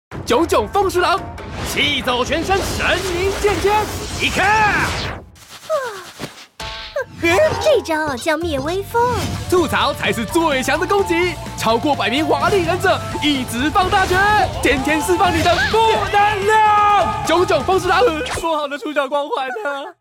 國語配音 男性配音員